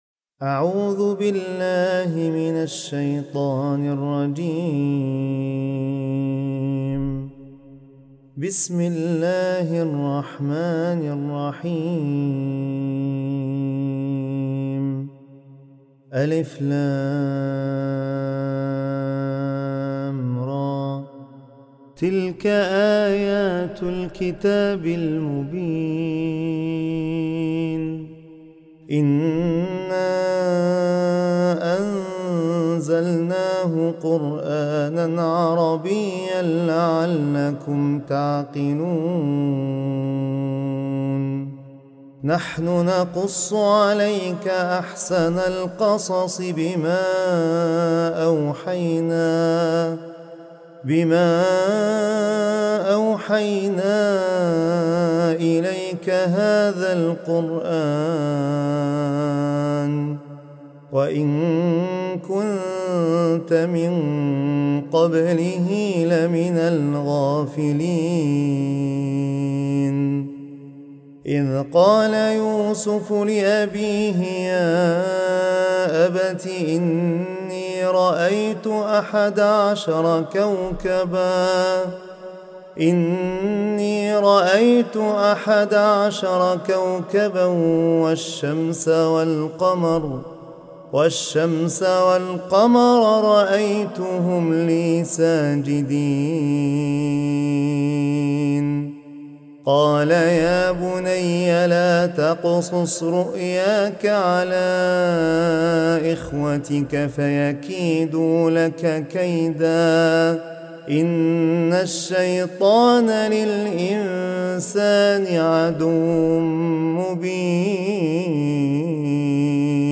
التلاوات القرآنية || تلاوة لسورة { يوسف }